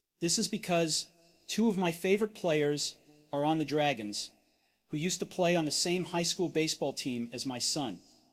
→ /ðɪs ɪz bɪˈkʌz tu əv maɪ ˈfeɪvərɪt ˈpleɪərz ɑr ɑn ðə ˈdræɡənz huː juzd tə pleɪ ɑn ðə seɪm ˈhaɪ ˌskul ˈbeɪsˌbɔl tim æz maɪ sʌn./